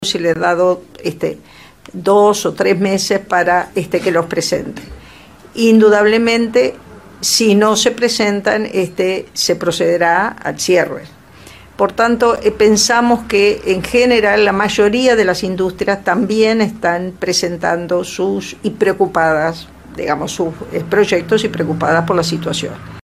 Palabras de Eneida de León